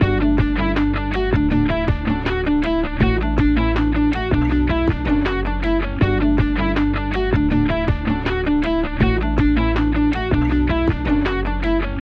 Without Bitcrusher
Bitcrusher_before.mp3